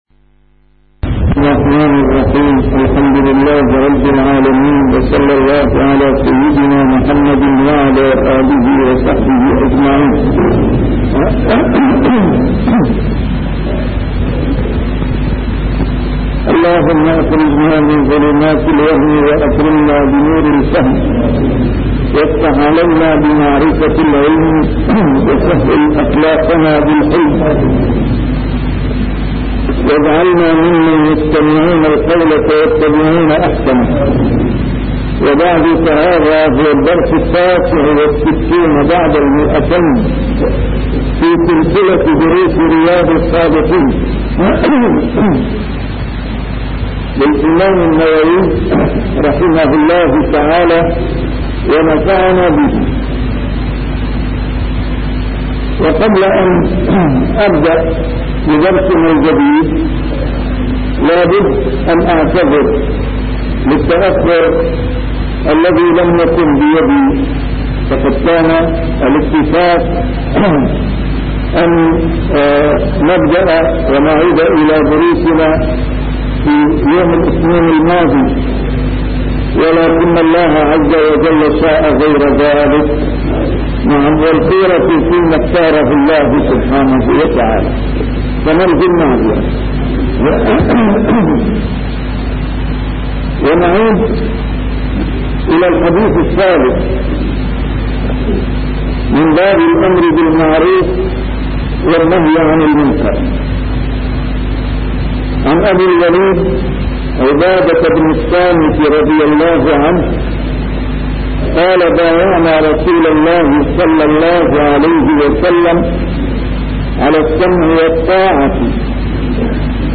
A MARTYR SCHOLAR: IMAM MUHAMMAD SAEED RAMADAN AL-BOUTI - الدروس العلمية - شرح كتاب رياض الصالحين - 269- شرح رياض الصالحين: الأمر بالمعروف